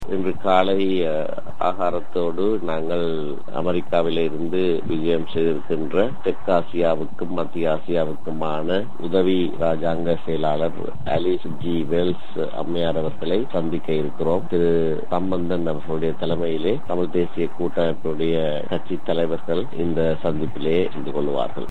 இவை குறித்த விபரங்களை கூட்டமைப்பின் பேச்சாளரும், நாடாளுமன்ற உறுப்பினருமான எம்.ஏ.சுமந்திரன் வழங்குகிறார்.